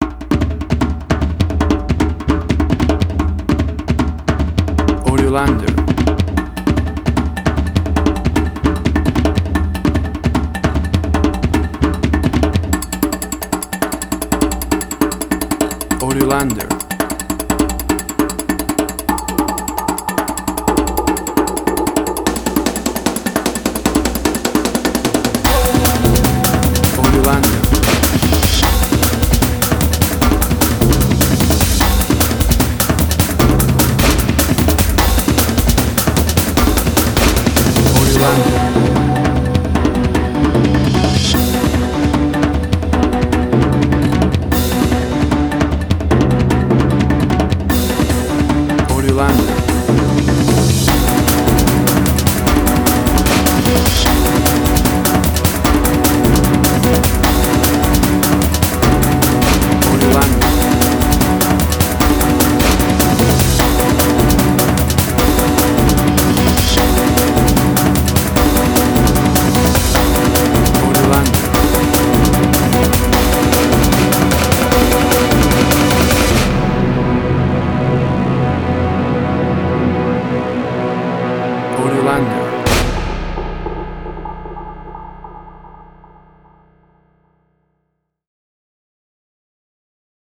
Suspense, Drama, Quirky, Emotional.
Tempo (BPM): 152